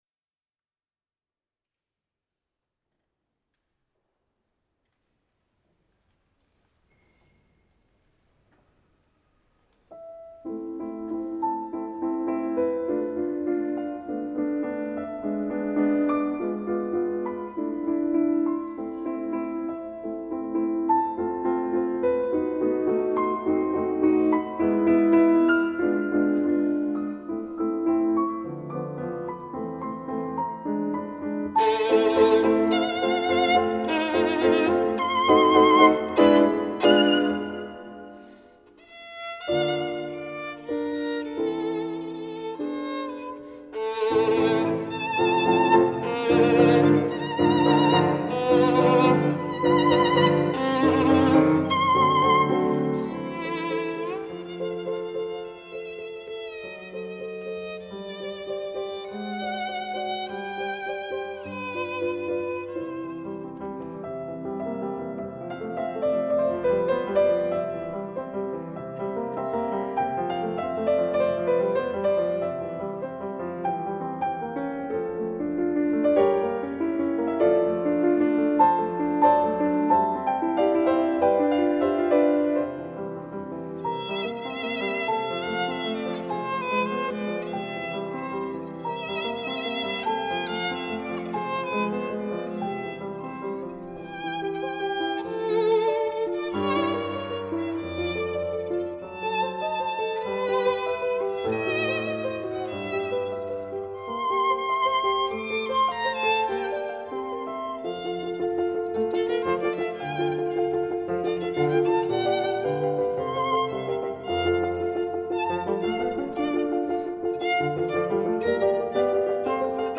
violino
pianoforte.